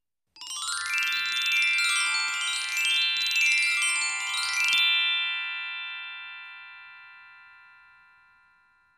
Звуки мутаций
Перевоплощение